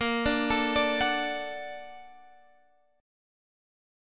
Bb+M7 chord